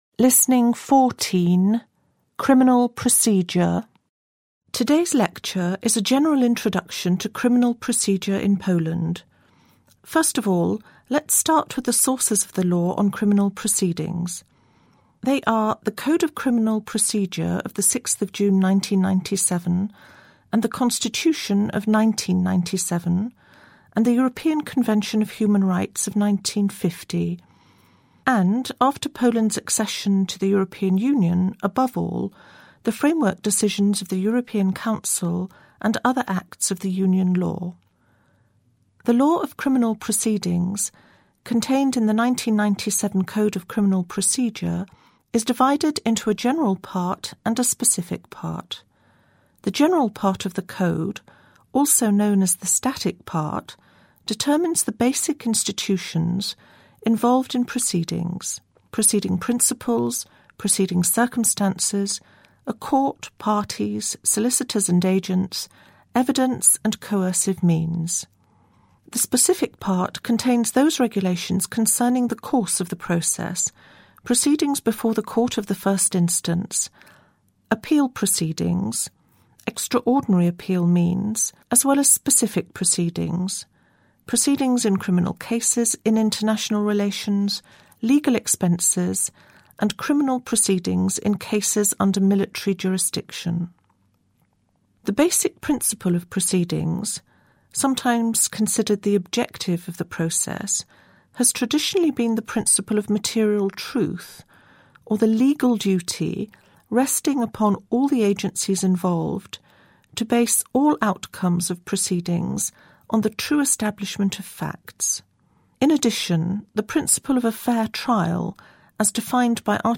Listening: Lecture on Criminal Procedure - 2.8 MB
track-14-lecture-on-criminal-procedure.mp3